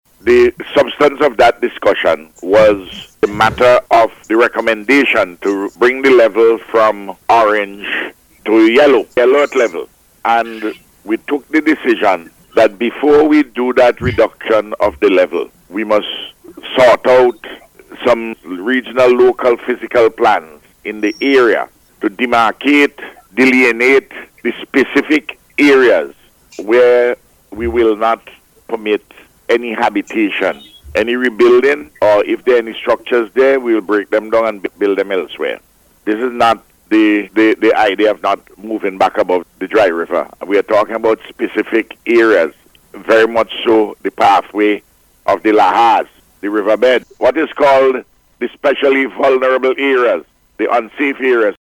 He made the point the Eyeing La Soufriere Programme this morning, as he reported on discussions held on the issue this week, during a meeting of Cabinet.